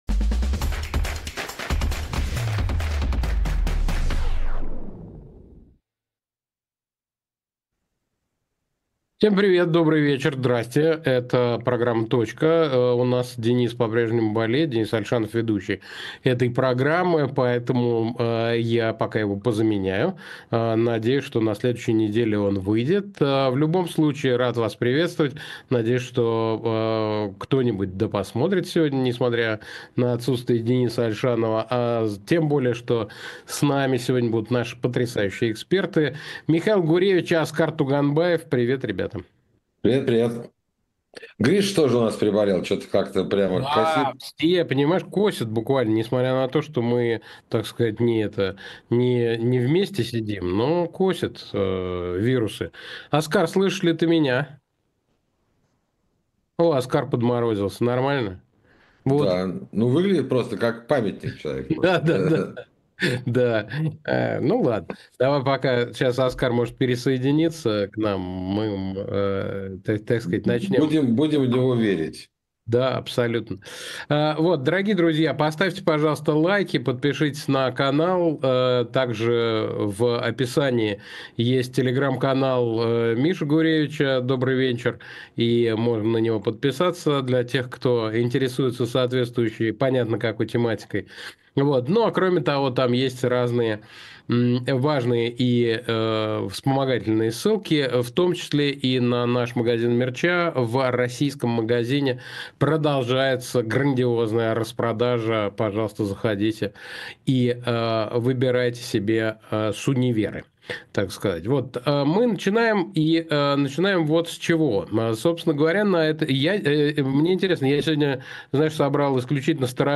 Александр Плющев говорит с экспертами про интернет и технологии в нашей жизни